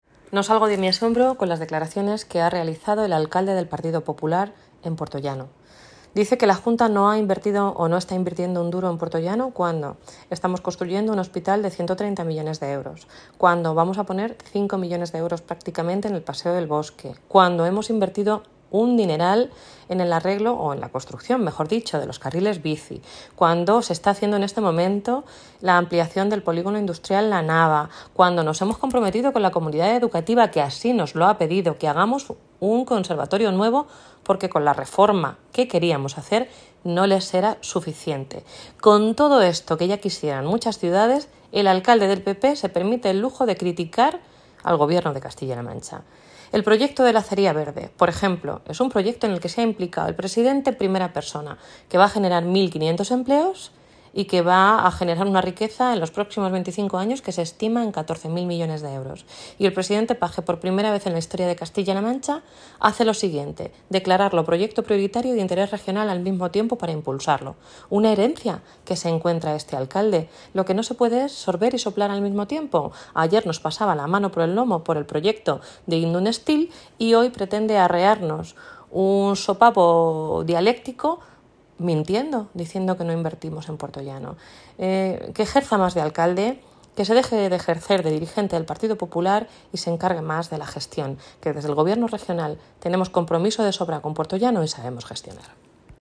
blanca_fernandez_asombro_declaraciones_alcalde_puertollano.mp3